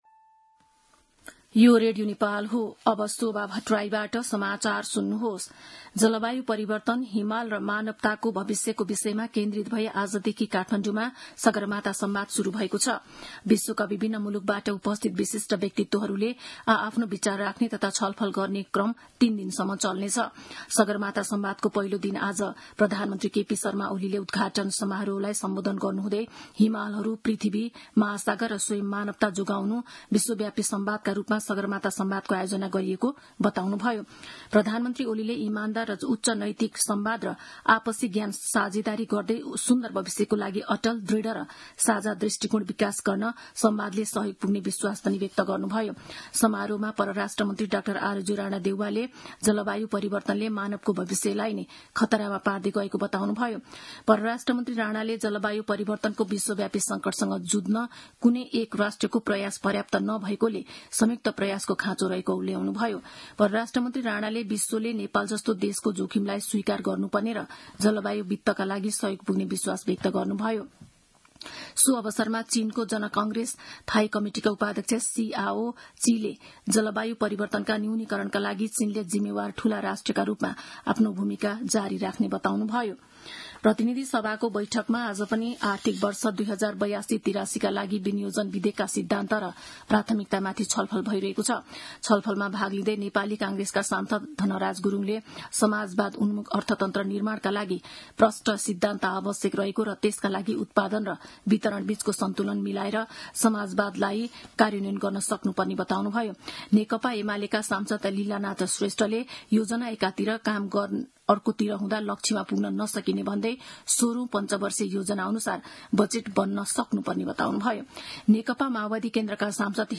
दिउँसो ४ बजेको नेपाली समाचार : २ जेठ , २०८२